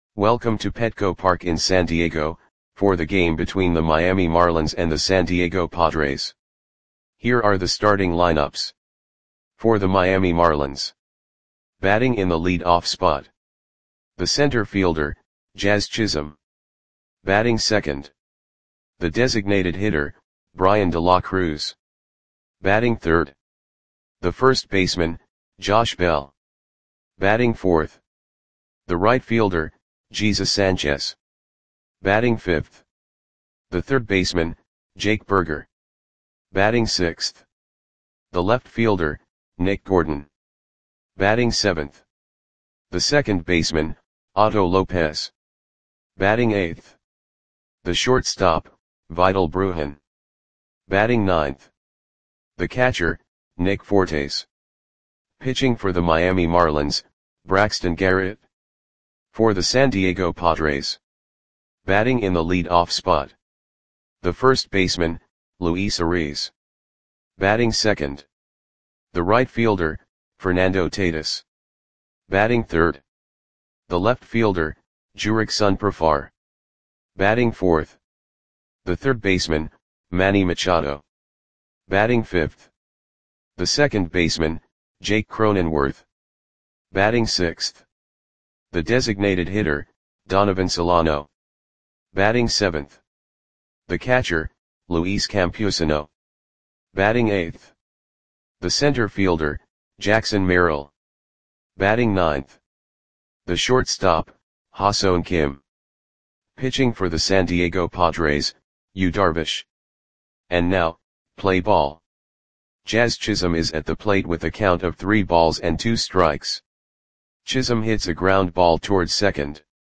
Audio Play-by-Play for San Diego Padres on May 29, 2024
Click the button below to listen to the audio play-by-play.